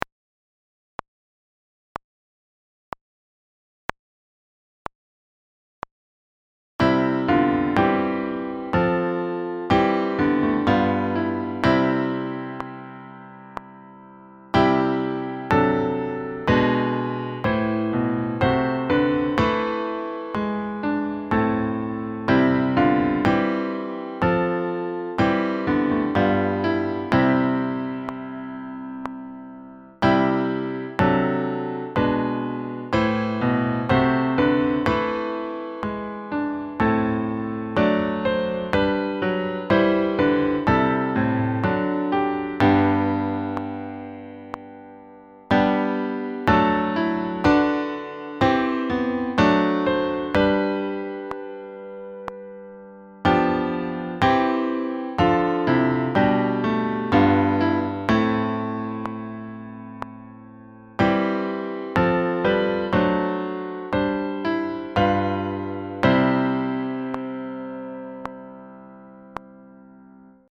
5. Each TRACK start with one measure of clicks to set the tempo and continue throughout the track
Chorale No. 7 (Bach) Four Part (w.metronome 68 bpm)